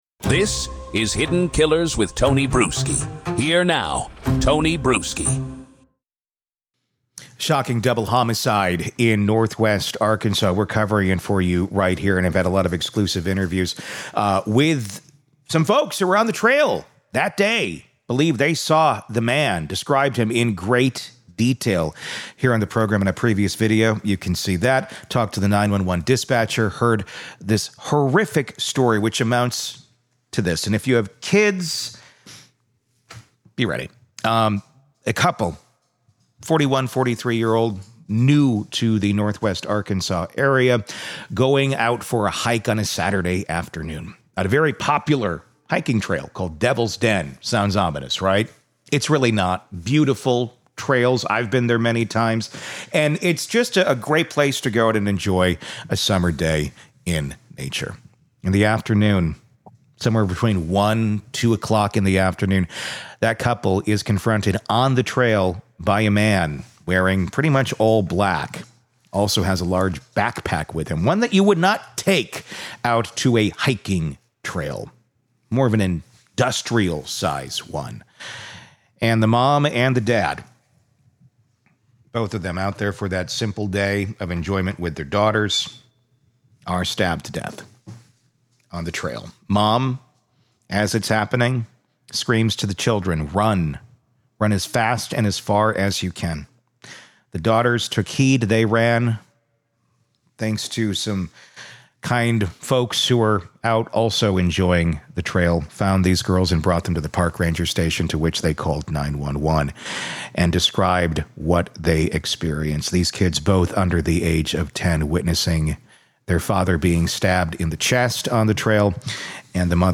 True Crime News & Interviews